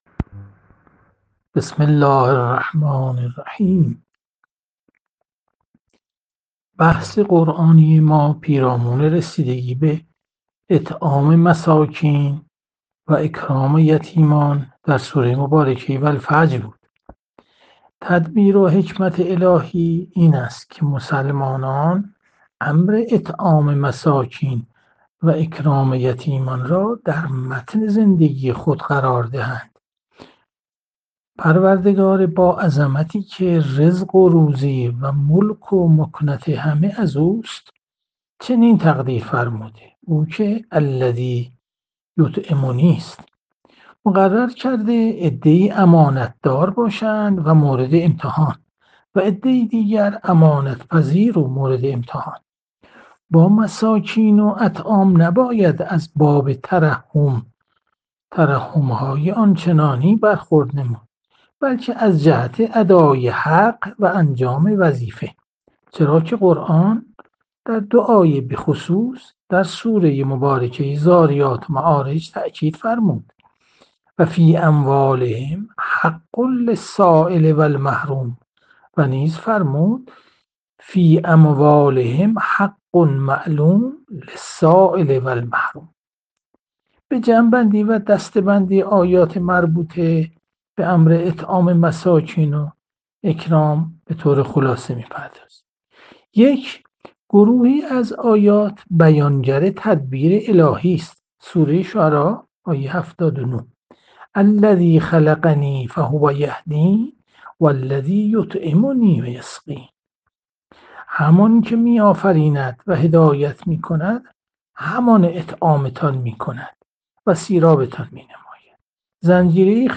جلسه مجازی هفتگی قرآنی، سوره فجر، 05 دی 1400